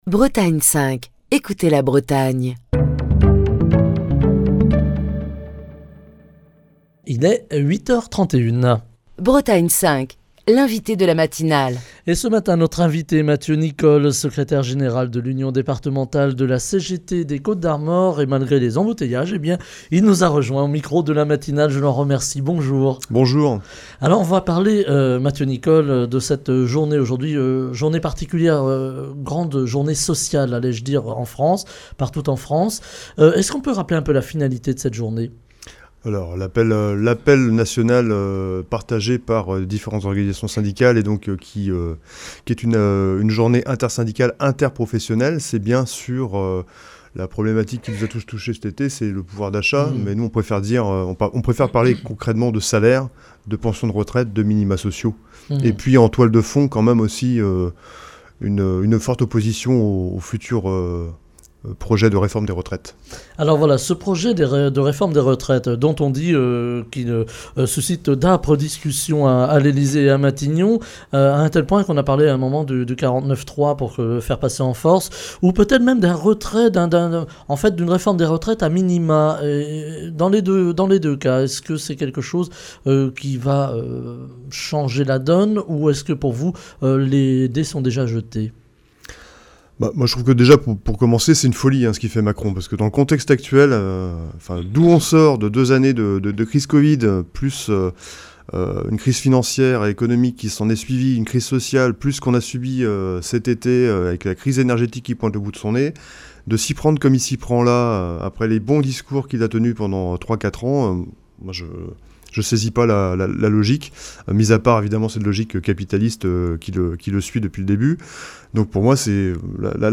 Émission du 29 septembre 2022.